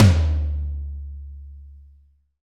Index of /90_sSampleCDs/Roland - Rhythm Section/KIT_Drum Kits 4/KIT_Ping Kit
TOM AC.TOM05.wav